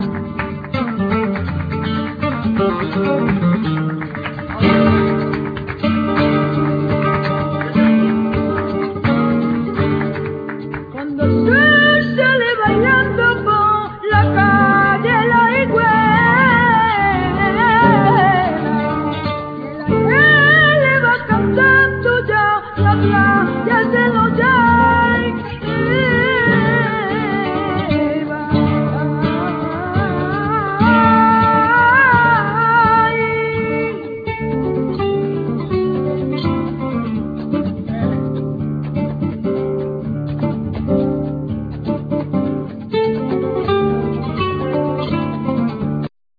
Guitar,Lute
Vocals,Hand Clapping
Percussion
Harmonica
E-Bass
Cajon,Hand Clapping,Jaleos